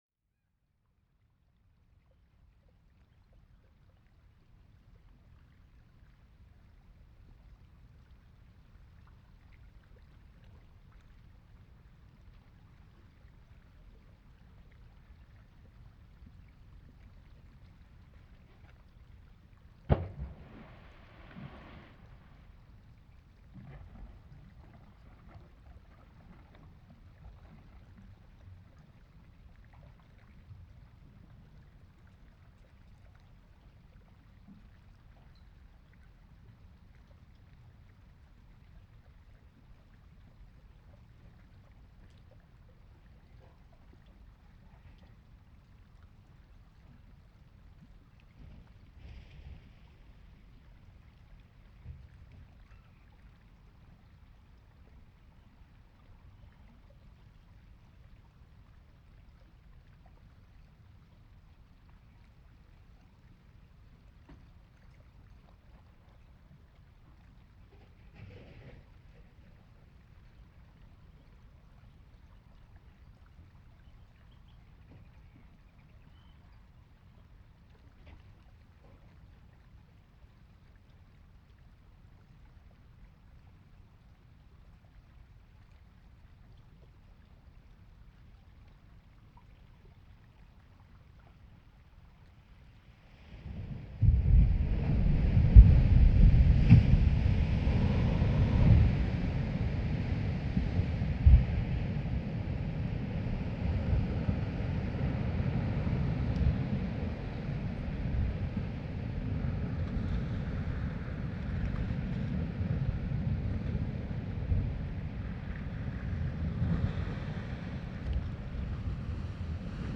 Breiðamerkurjökull glacier
Last summer Jökulsárlón lagoon and Breiðamerkurjökul glacier were my main targets.
breidamerkurjokull3.mp3